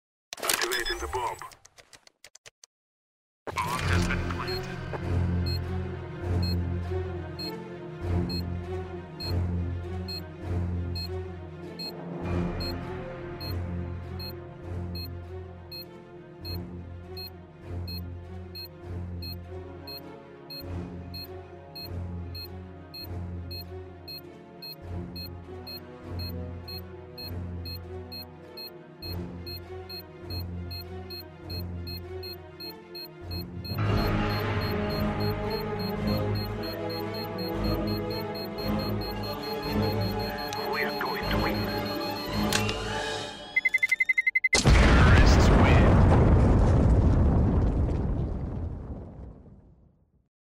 Звук таймера бомбы кс го